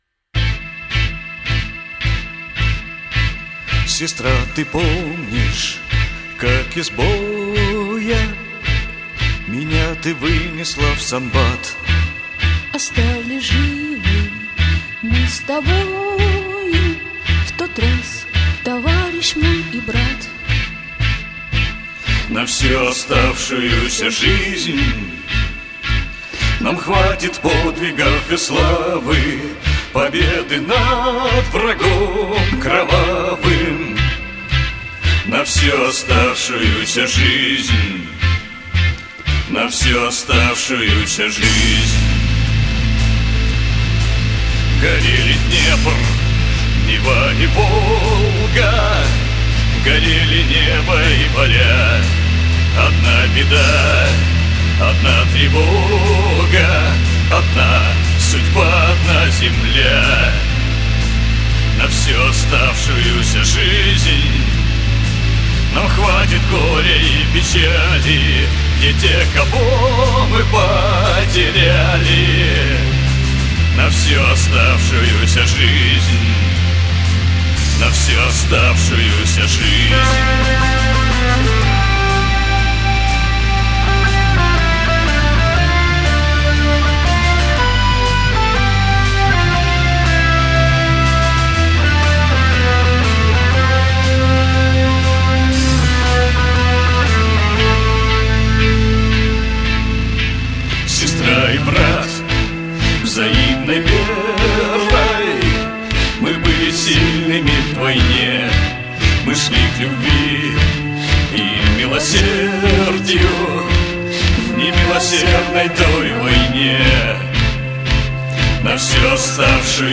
Песня отличная по тематике, но исполнение напористое, имхо.